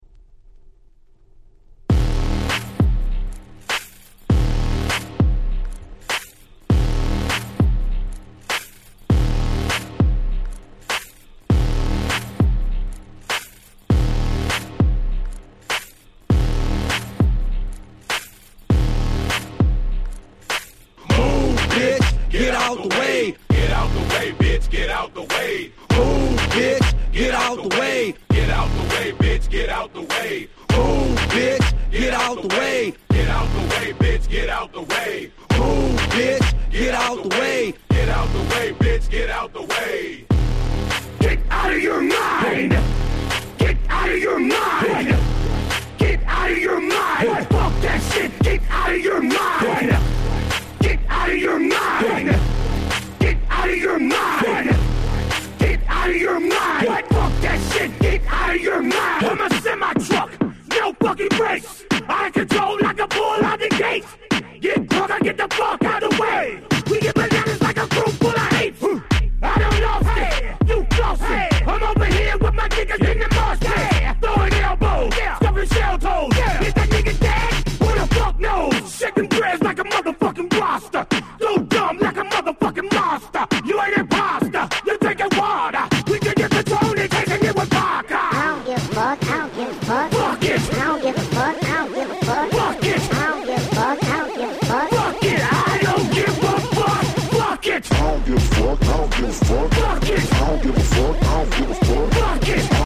White Press Only EDM/Hip Hop Remixes !!